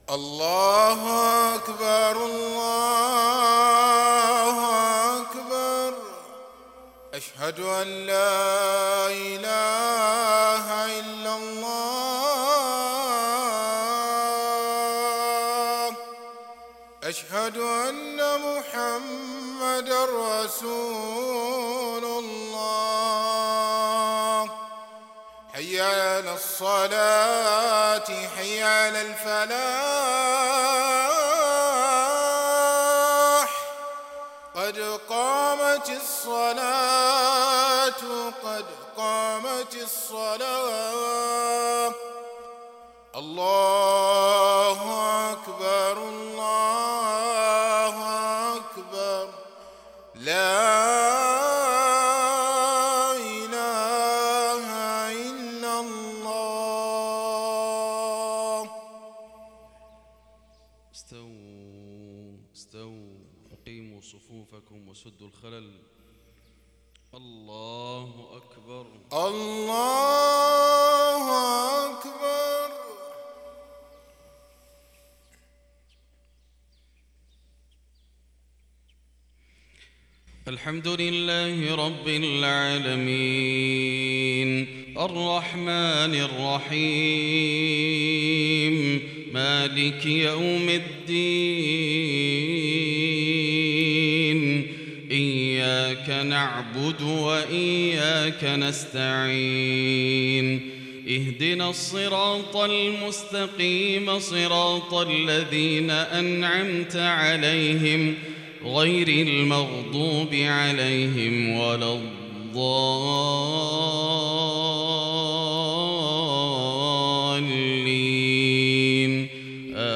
صلاة العشاء الشيخان ماهر المعيقلي وعـبـد المحسن القاسم قرأ الشيخ ماهر المعيقلي أوائل ســورة المؤمنون بينما قرأ الشيخ عـبـد المحسن القاسم من ســورة سبأ